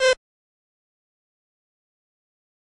Synth Bass (Sloppy Toppy).wav